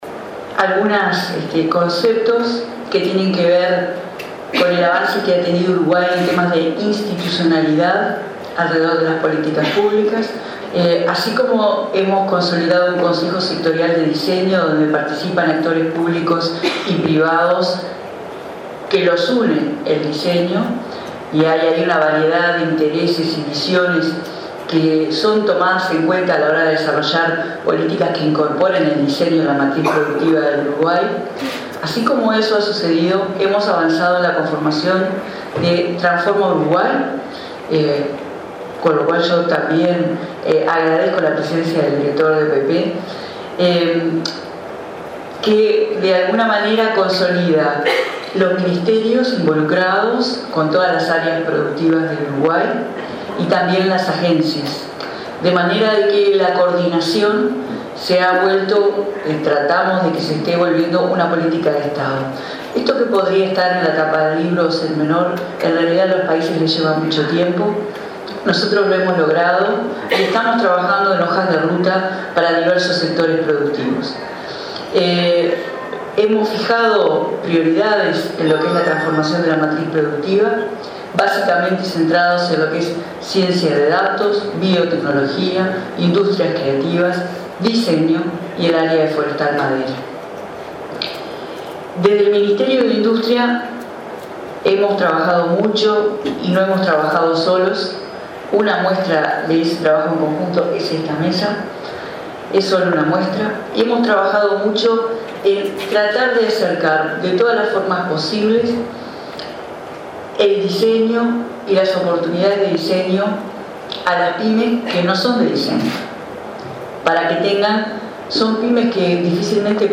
“Uruguay consolida nuevos sectores productivos con una institucionalidad que coordina al sector público con el privado”, señaló la ministra de Industria, Carolina Cosse, en la apertura del IX Encuentro de Políticas Públicas y Diseño. Destacó la importancia del Consejo Sectorial de Diseño y el programa Uruguay Transforma, que fomentan las industrias del futuro.